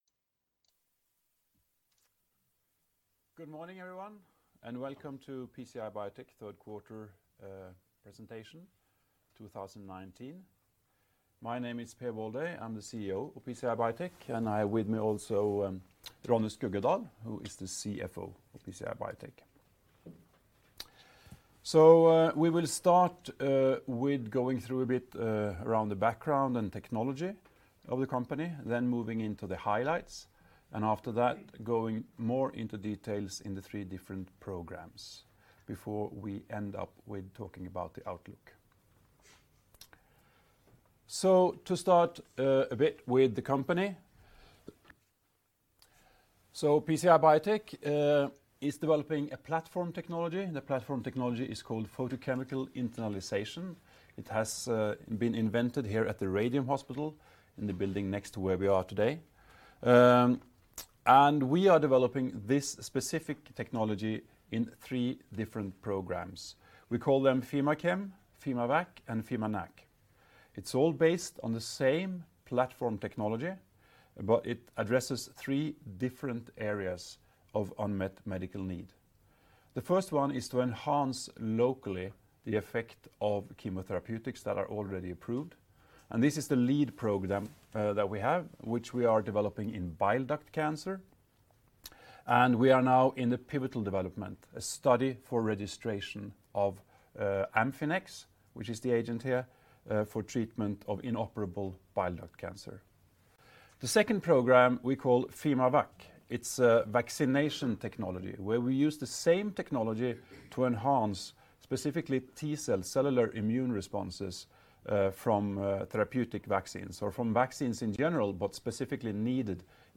Litt ved siden av diskusjonen som er nå, men jeg har tatt meg arbeidet å få henta ned lyden fra Q3 2019 presentasjonen(siste kvartalspresentasjon) og redigert bort hosting og høye støylyder slik at en fint kan høre gjennom filen nå uten noe problemer. Fra originalen så er det litt lavere lyd de første 15 sekundene, men resten er sånn rimelig greit. Ihvertfall ikke noe problemer for ørene å høre på.